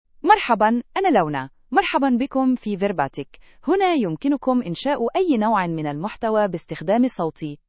Luna — Female Arabic (Standard) AI Voice | TTS, Voice Cloning & Video | Verbatik AI
Luna is a female AI voice for Arabic (Standard).
Voice sample
Female
Luna delivers clear pronunciation with authentic Standard Arabic intonation, making your content sound professionally produced.